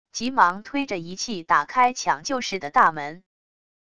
急忙推着仪器打开抢救室的大门wav音频